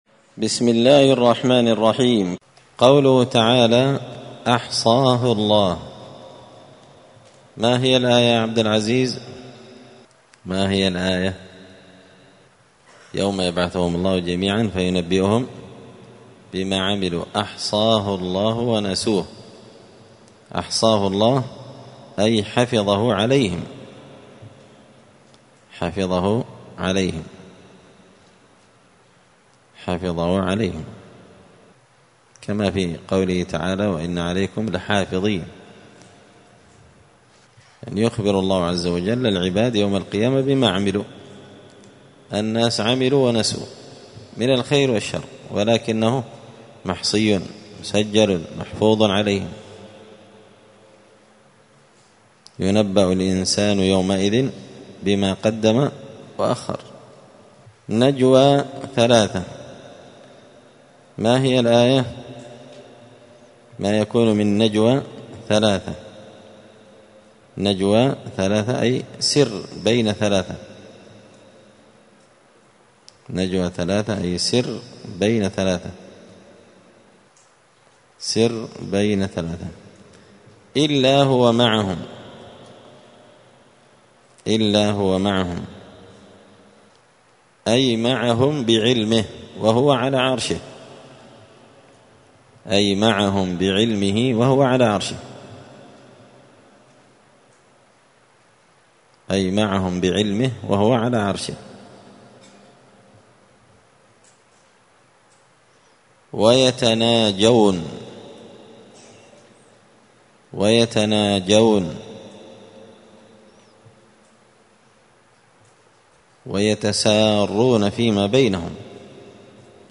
زبدة الأقوال في غريب كلام المتعال الدرس الثاني والعشرون بعد المائة (122)